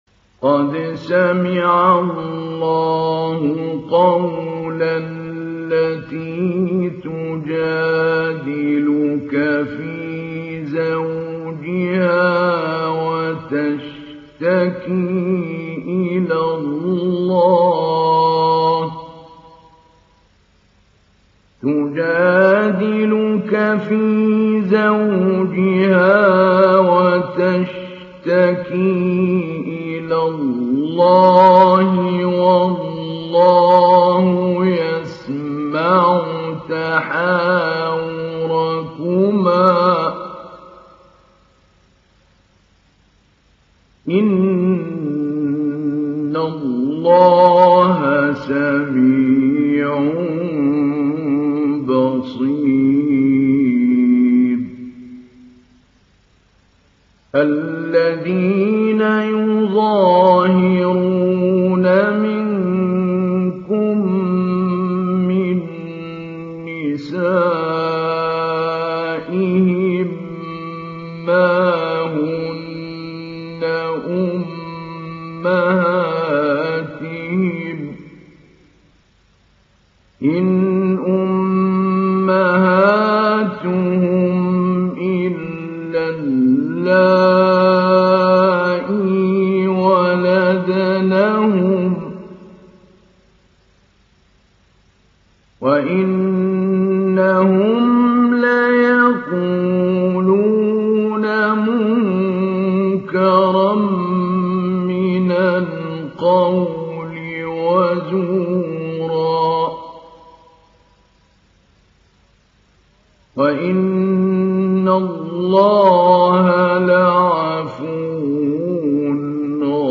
İndir Mücadele Suresi Mahmoud Ali Albanna Mujawwad